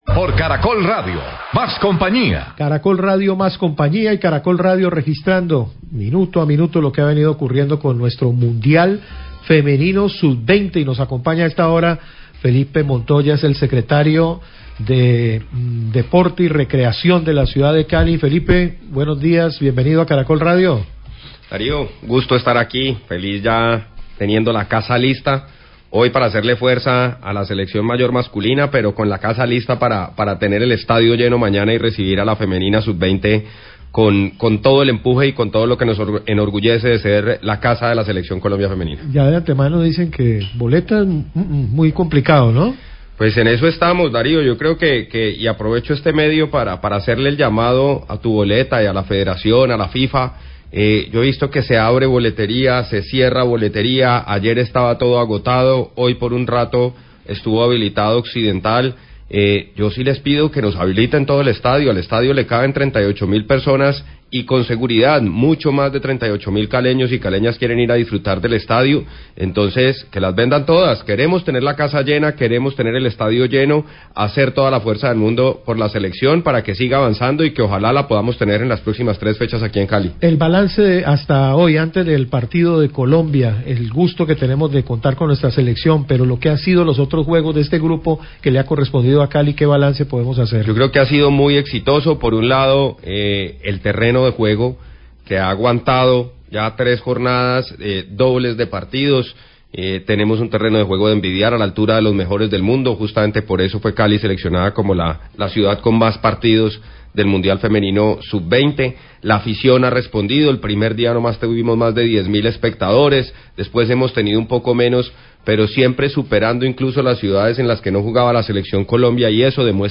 Radio
El Secretario de Deportes y Recreación de Cali, Felipe Montoya, habla de los preparativos para el partido de la selección Colombia en el Mundial de Futbol Femenino Sub 20. Hace un balance positivo de los anteriores juegos desarrollados en la ciudad.